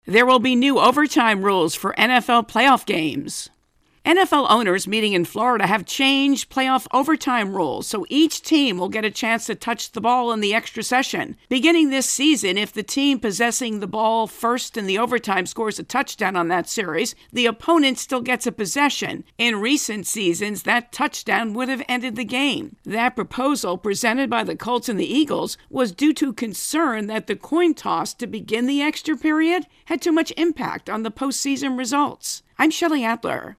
NFL intro and voicer